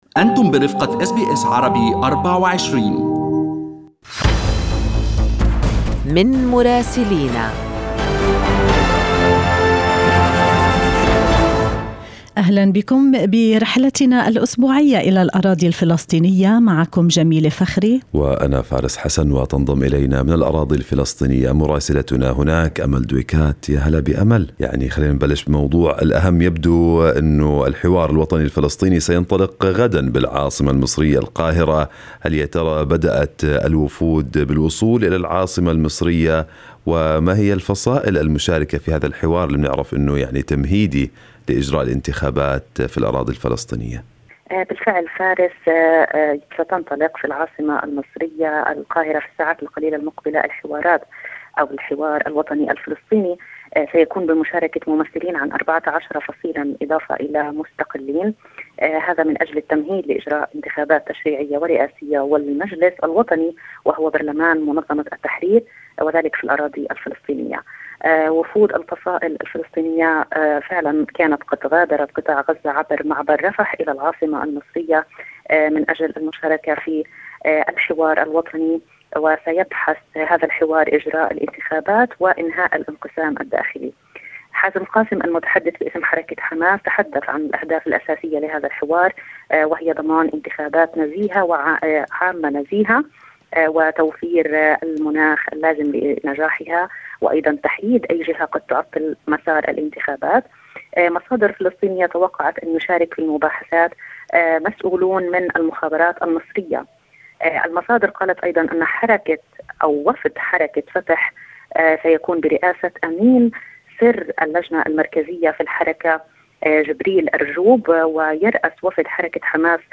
من مراسلينا: أخبار الأراضي الفلسطينية في أسبوع 8/2/2021